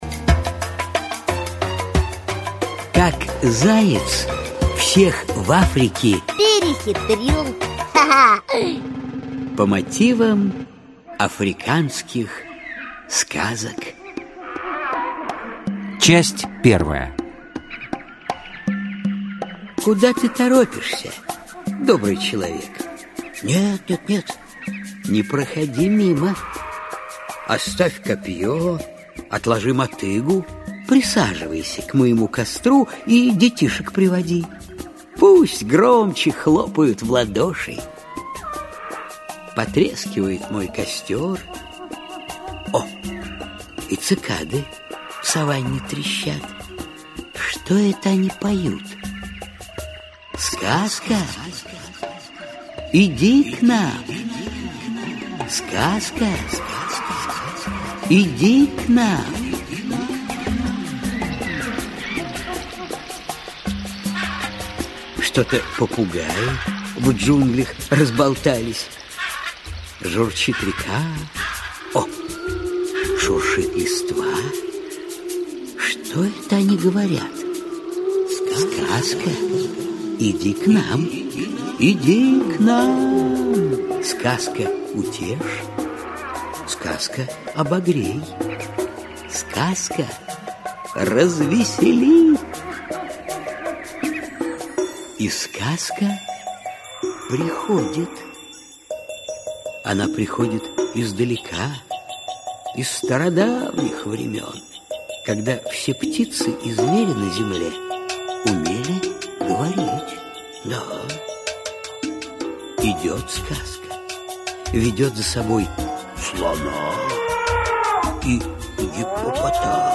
Аудиокнига Как заяц в Африке всех перехитрил. Аудиоспектакль | Библиотека аудиокниг
Аудиоспектакль Автор Народное творчество Читает аудиокнигу Александр Леньков.